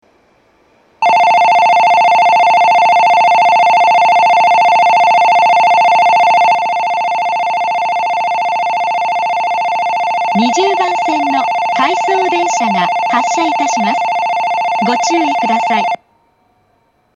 発車時には全ホームROMベルが流れます。
遅くとも東北新幹線全線開業時には、発車ベルに低音ノイズが被るようになっています。ただし、新幹線の音がうるさいので密着収録していてもほとんどわかりません。
２０番線発車ベル 回送電車の放送です。